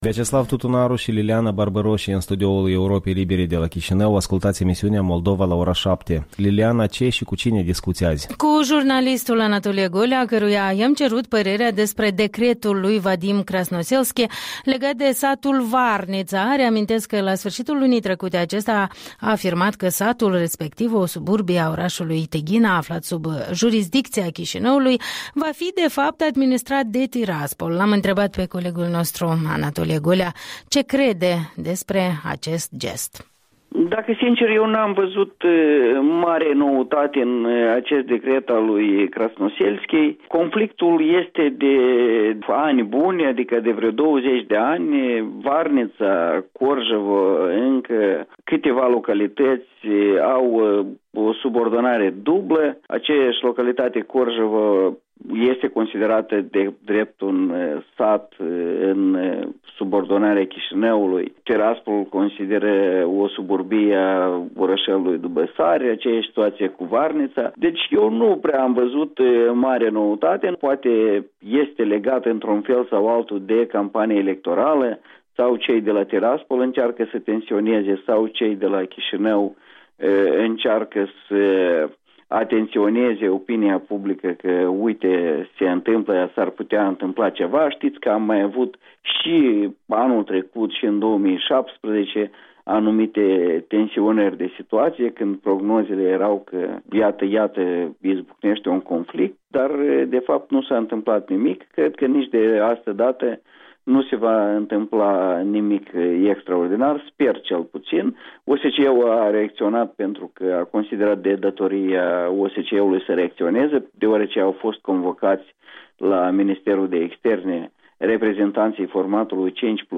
Interviul dimineții despre ultime evoluții în relațiile dintre Chișinău și Tiraspol.